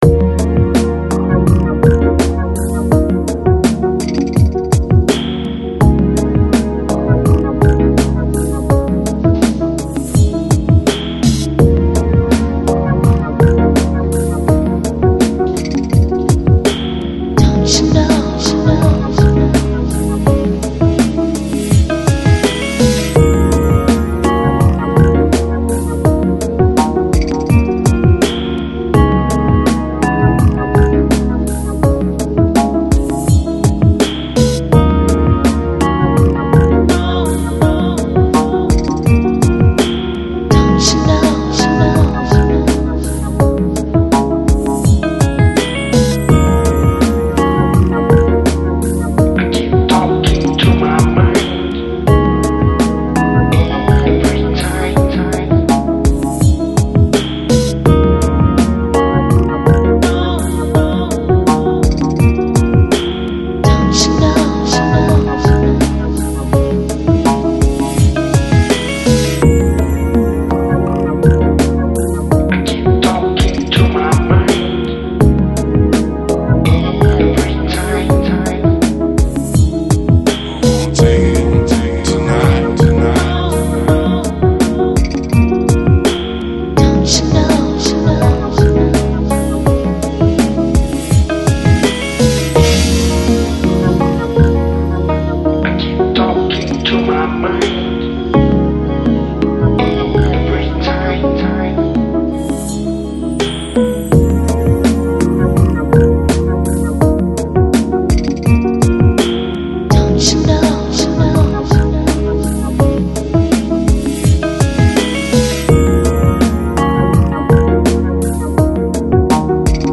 Lounge, Lo-Fi, Ambient, New Age, Chill Out Носитель